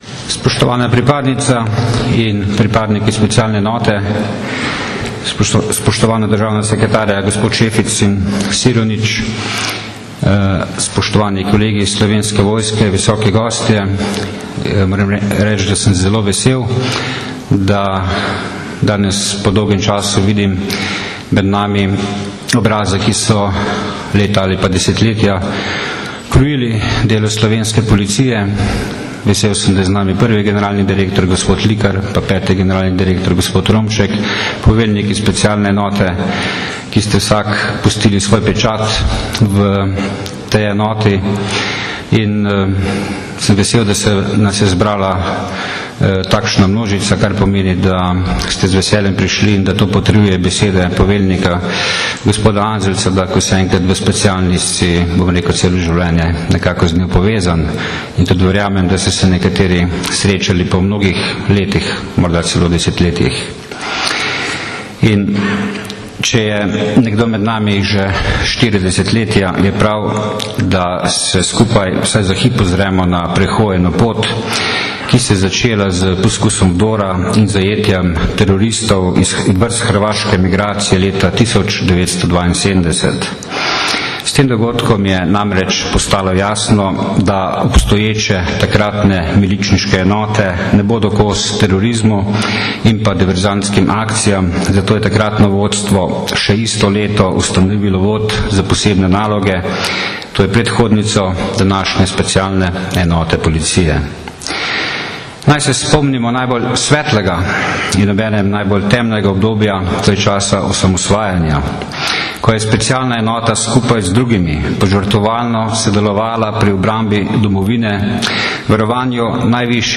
Slovesno praznovanje je bilo 22. maja 2013 v Policijski akademiji v Tacnu v Ljubljani.
Slavnostni nagovor generalnega direktorja policije Stanislava Venigerja   (velja govorjena beseda)
Zvočni posnetek govora Stanislava Venigerja (mp3)